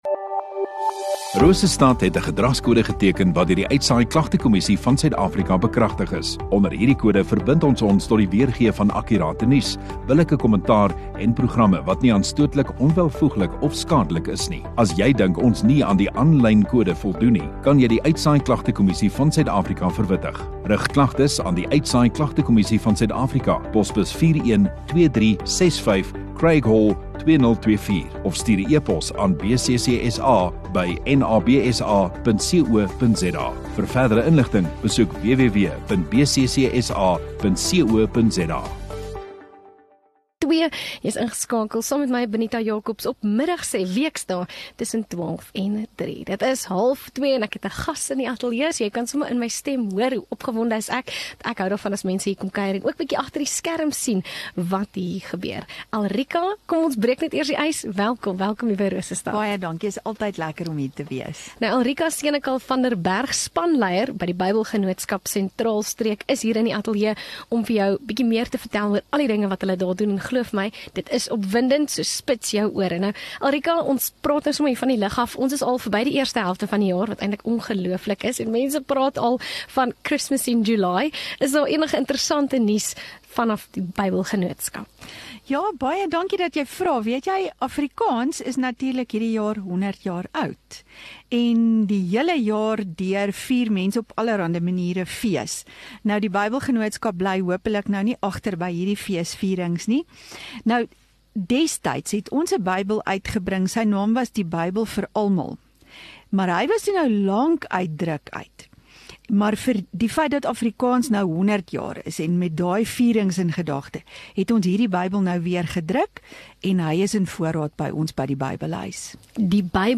Radio Rosestad View Promo Continue Radio Rosestad Install Gemeenskap Onderhoude 15 Jul Bybelgenootskap